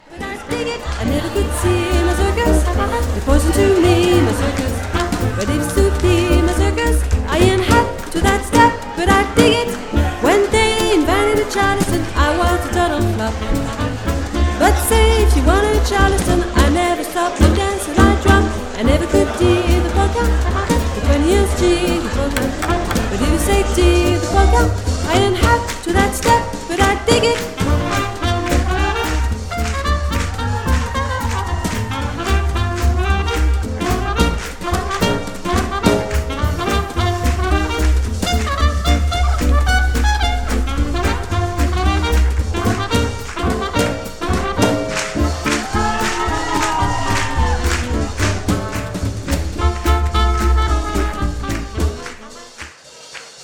arrangements pleins de swing
chant, washboard
trompette, cornet
saxophones alto et soprano
guitare
contrebasse
batterie